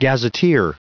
Prononciation du mot gazetteer en anglais (fichier audio)
Prononciation du mot : gazetteer